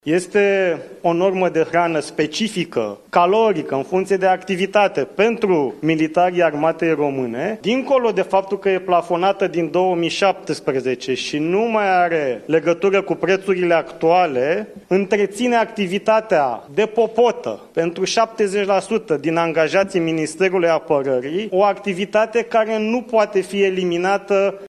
Ministrul Radu Miruță a declarat că nu va fi de acord și nu-și va pune semnătura pe o propunere privind eliminarea unei norme de hrană, care, subliniază ministrul, este plafonată din 2017.